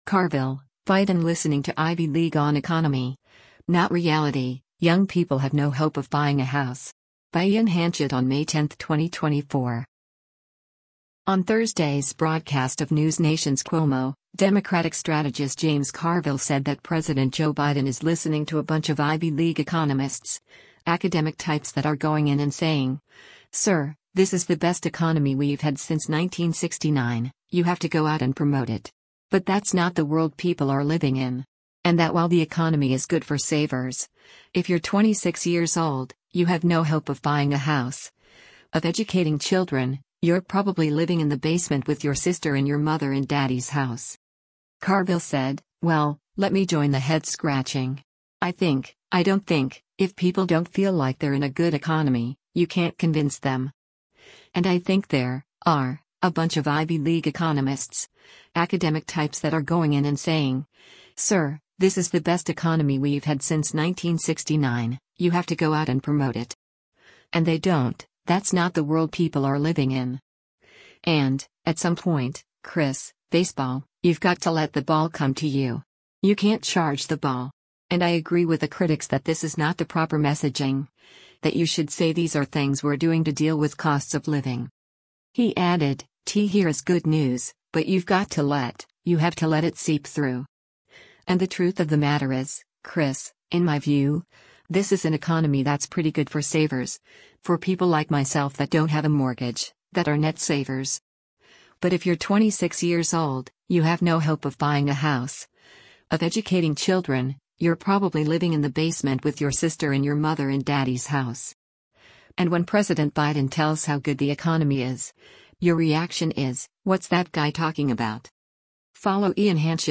On Thursday’s broadcast of NewsNation’s “Cuomo,” Democratic strategist James Carville said that President Joe Biden is listening to “a bunch of Ivy League economists, academic types that are going in and saying, sir, this is the best economy we’ve had since 1969, you have to go out and promote it.”